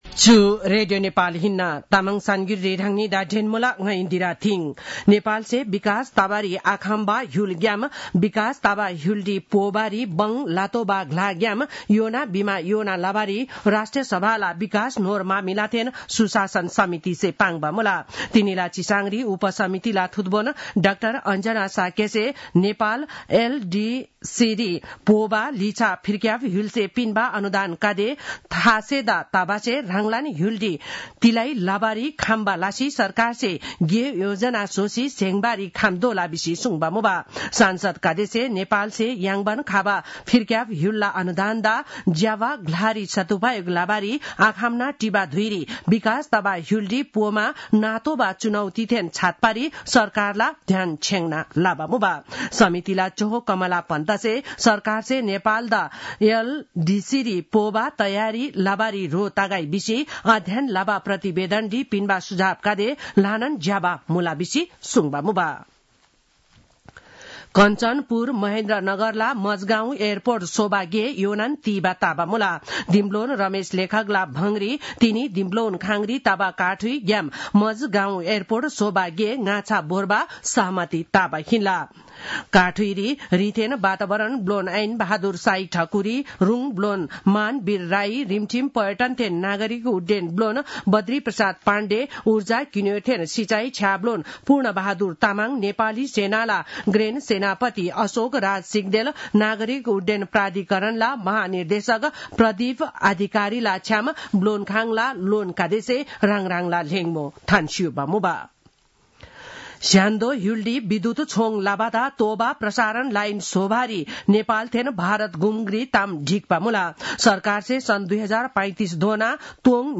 तामाङ भाषाको समाचार : ३ फागुन , २०८१